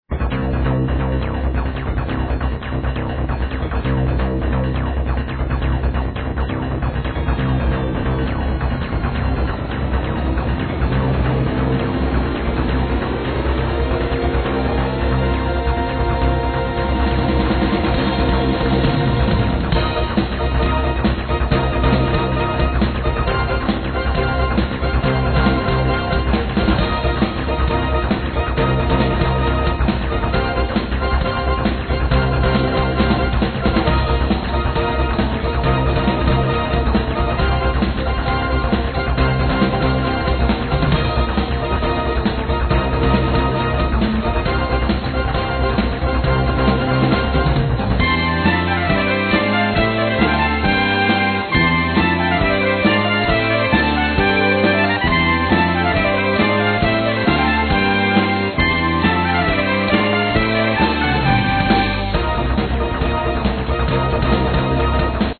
Keyboards, Vocals
Drums, Percussions, Bass guitar, Vocals
Flute
Cello
Violin
Trumpet
Guitar